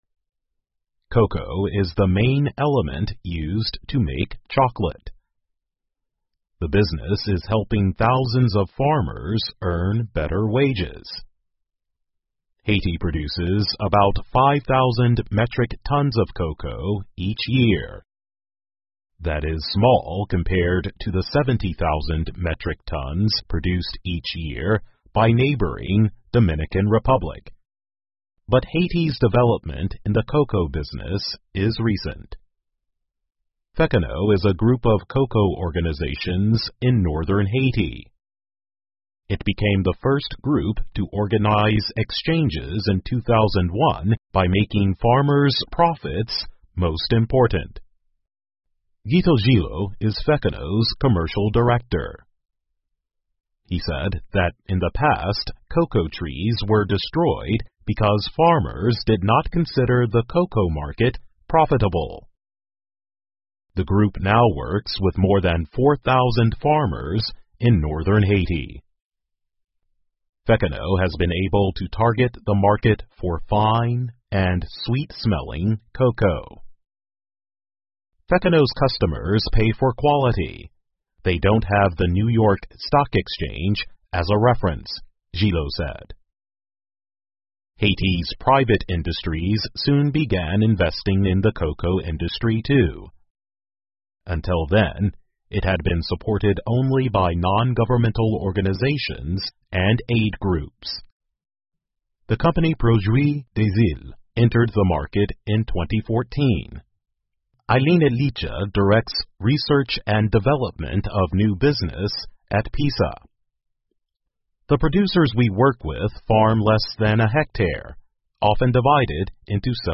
VOA慢速英语2020--海地的可可需要国际认可 听力文件下载—在线英语听力室